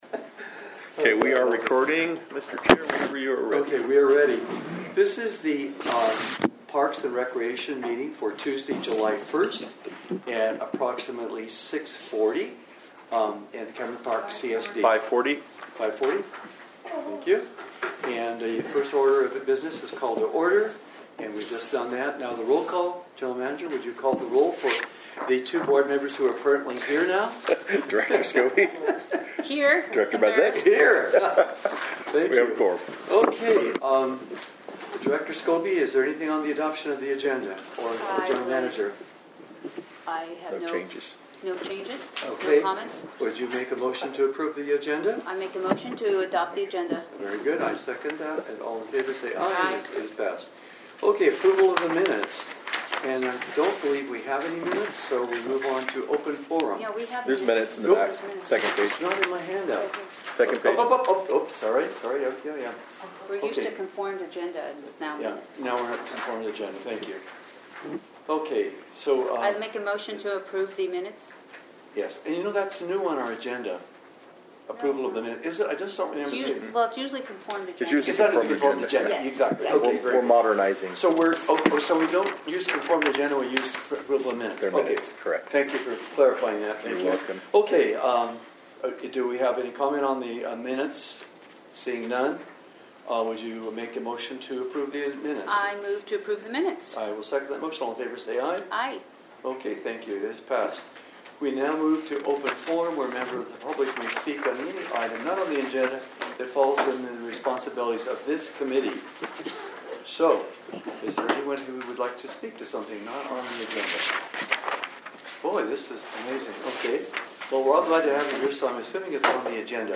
Parks & Recreation Committee Regular Meeting